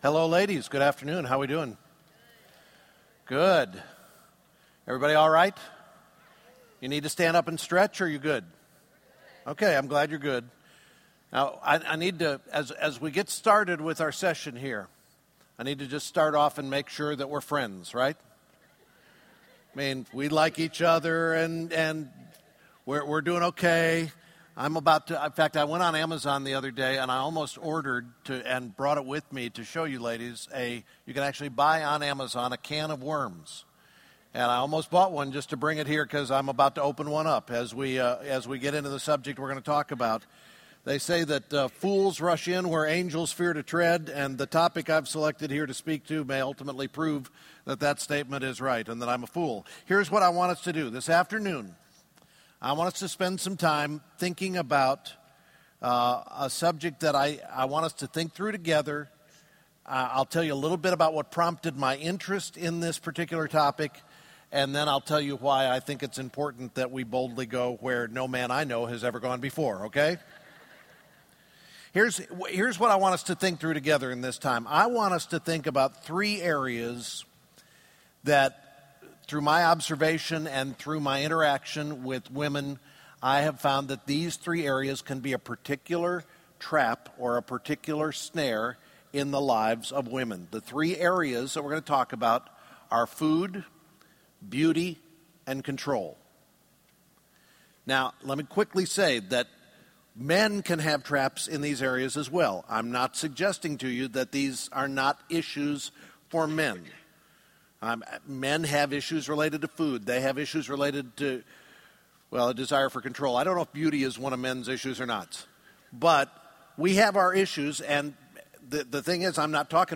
Food, Beauty, and Control: Three Snares Women Face | True Woman '10 Fort Worth | Events | Revive Our Hearts